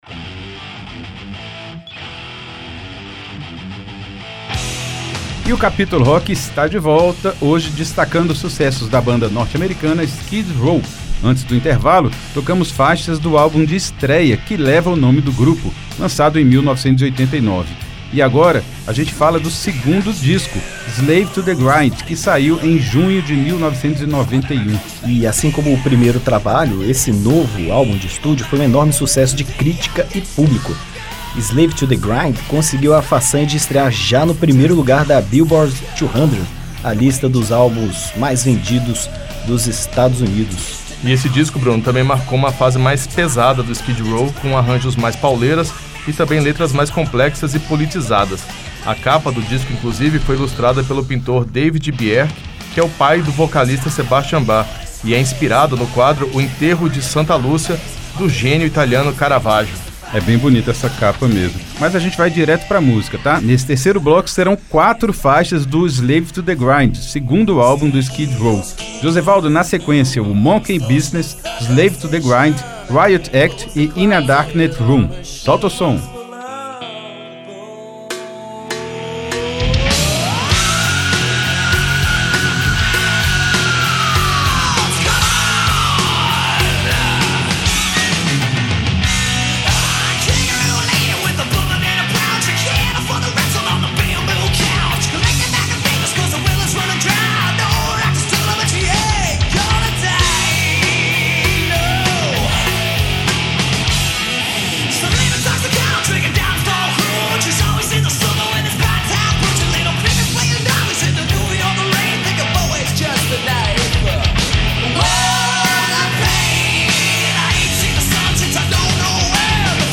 glam metal
vocais
guitarra
baixo
bateria